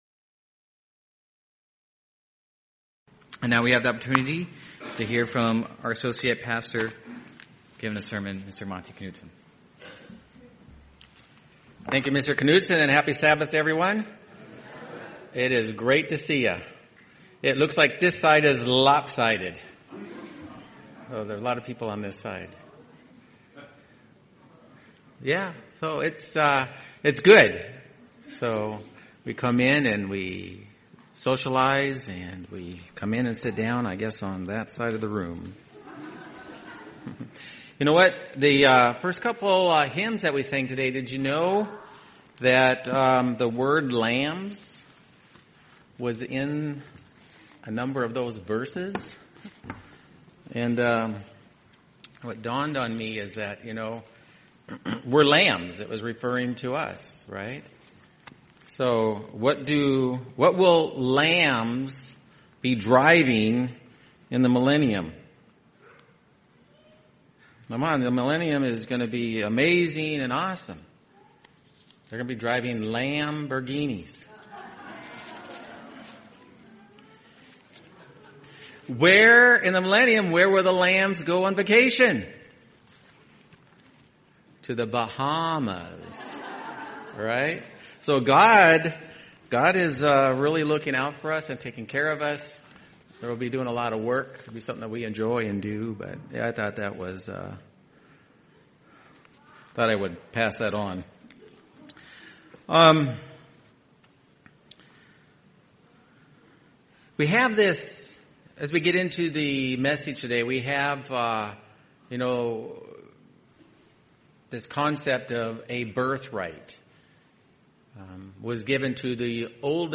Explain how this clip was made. Given in Phoenix East, AZ